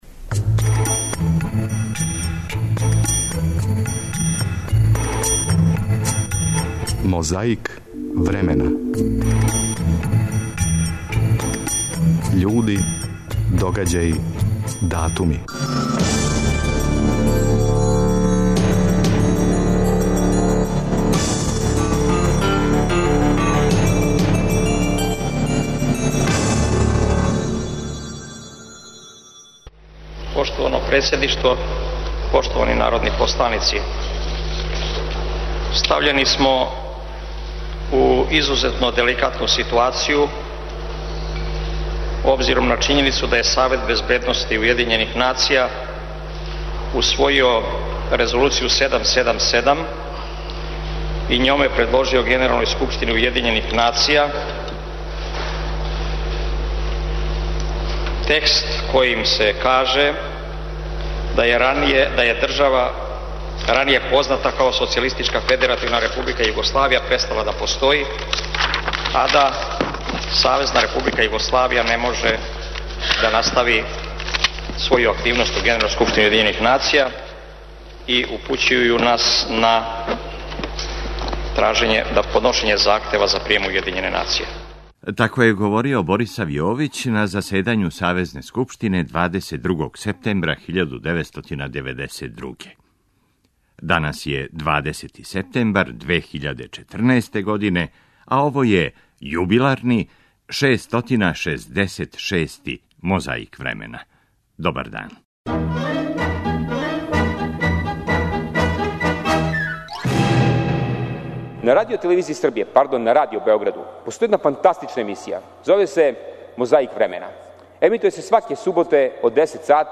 Како је говорио Борисав Јовић на заседању Савезне скупштине 22. септембра 1992., слушајте у 666 Мозаику времена...